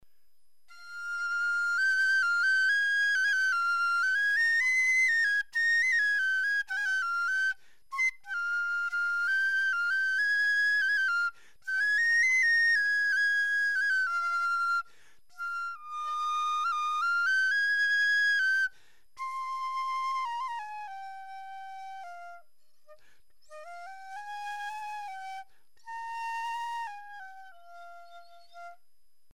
URM Sonos de Sardigna: nuovi strumenti - Flauti traversi in ottone
FlautoTraversoOttone.mp3